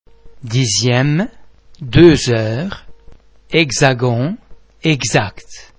The French [z] sound is normally pronounced [z] as in the English words zero, zebra etc.
·the [x] is pronounced [z] or [gz] in